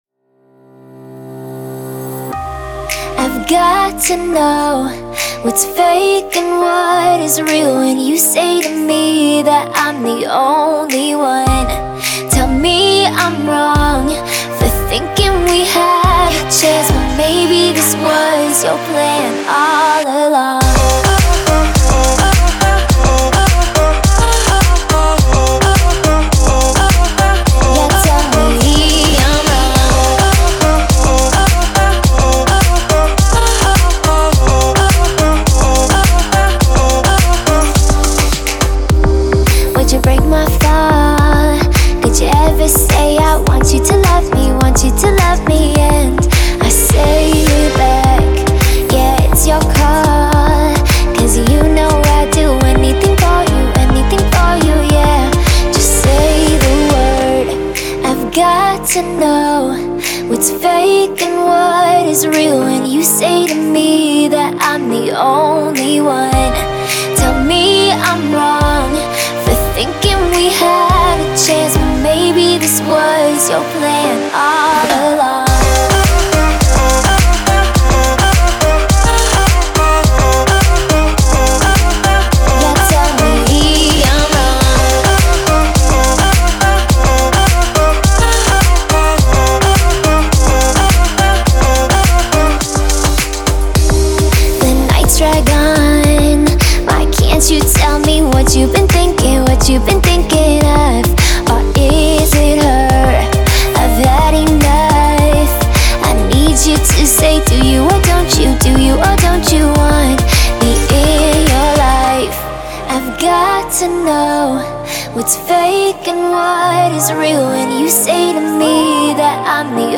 атмосферная электронная композиция